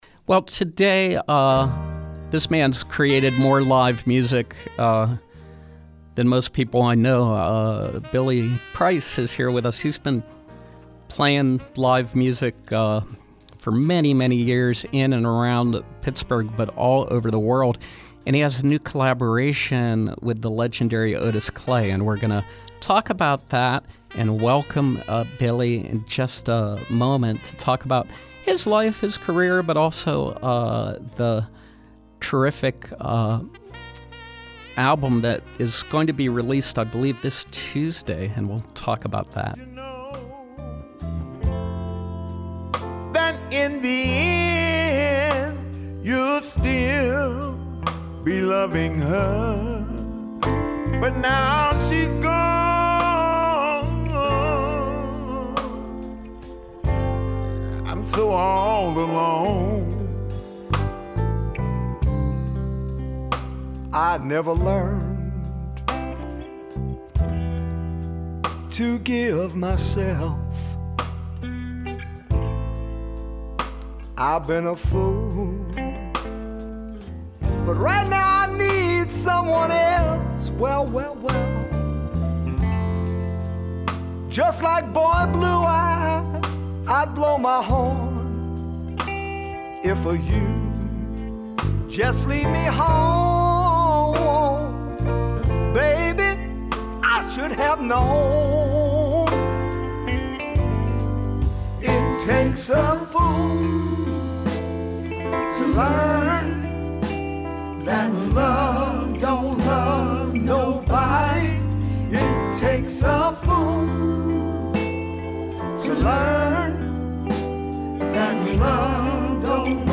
Pittsburgh soul-singer